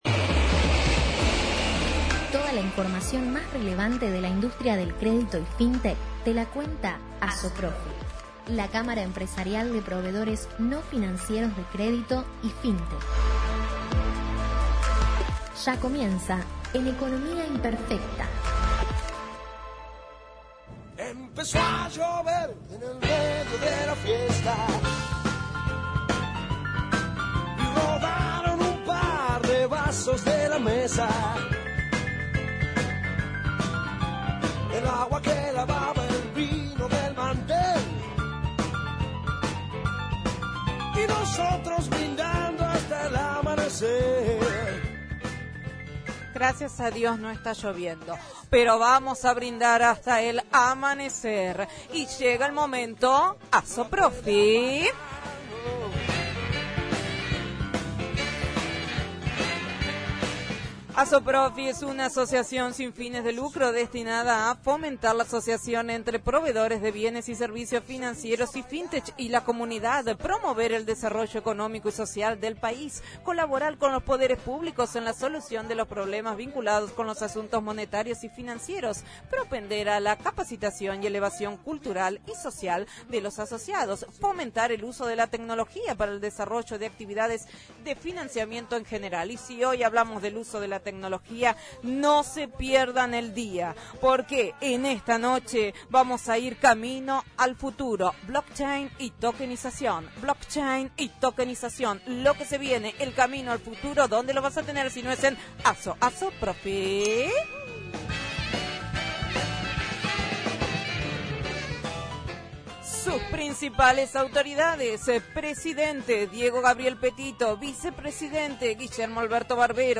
Para volver a escuchar la Columna Radial ingresando aquí: